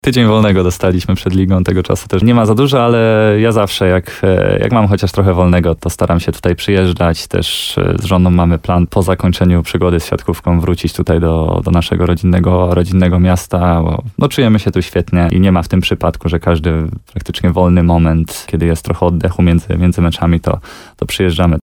Siatkarz Marcin Janusz godnie reprezentował Polskę, a teraz w końcu ma chwilę, by odwiedzić rodzinny Nowy Sącz, a przy tej okazji porozmawiać z radiem RDN Nowy Sącz.
Rozmowa z Marcinem Januszem: Tagi: Nowy Sącz siatkówka reprezentacja Polski Marcin Janusz